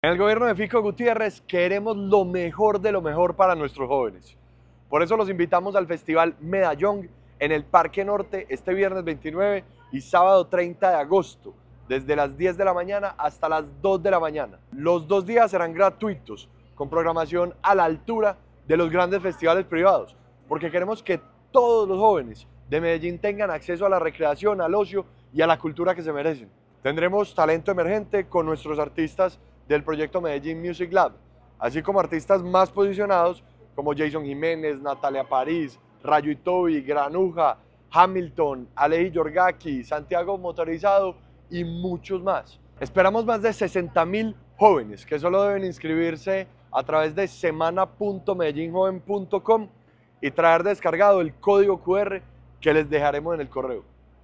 Declaraciones-del-secretario-de-la-Juventud-Ricardo-Jaramillo.mp3